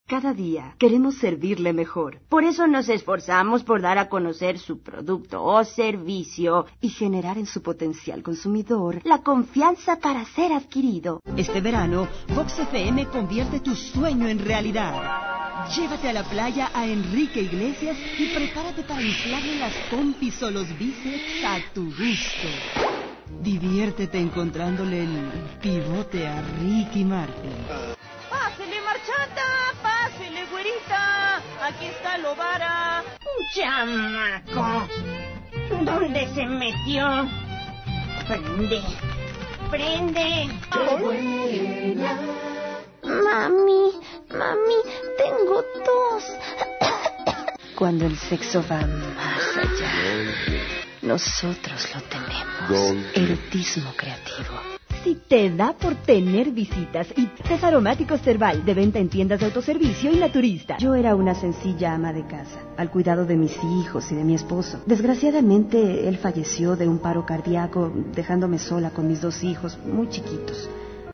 Desde un tono cálido y amigable hasta uno firme y convincente, mi voz conecta con las audiencias.
Escucha mi Demo